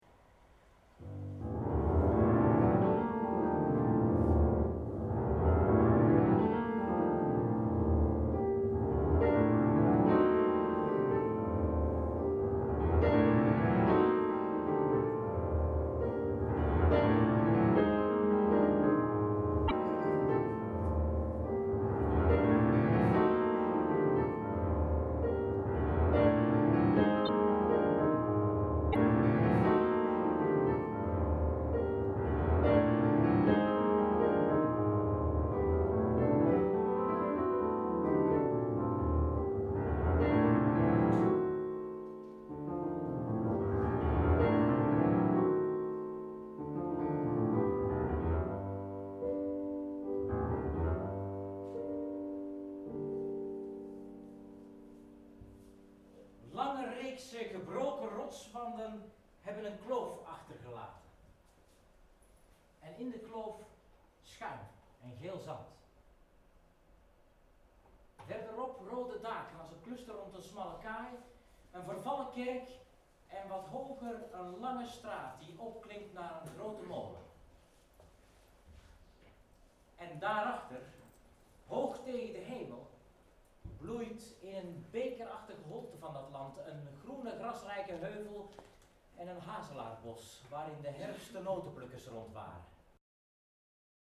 Muziektheater voor volwassenen
Richard Strauss componeerde eind 19e eeuw een bijzonder en meeslepend melodrama voor piano en verteller: Enoch Arden, opus 38.
Muziekfragment
Piano